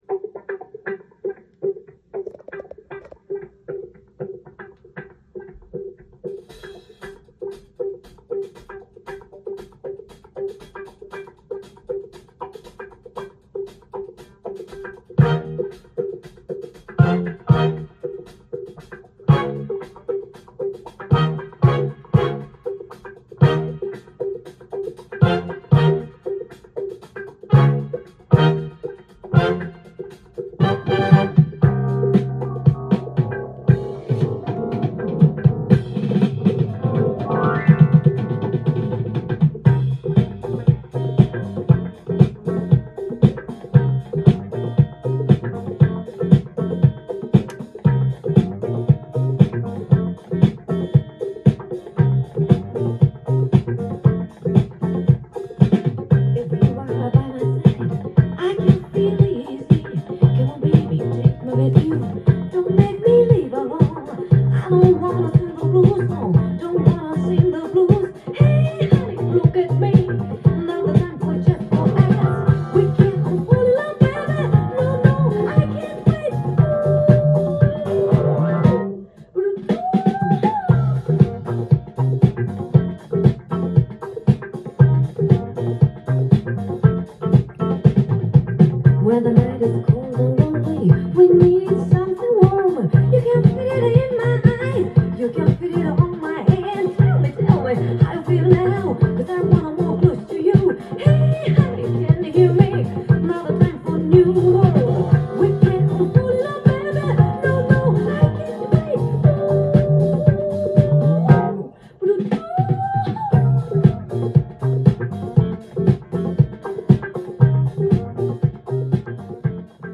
ジャンル：CITYPOP / POP
店頭で録音した音源の為、多少の外部音や音質の悪さはございますが、サンプルとしてご視聴ください。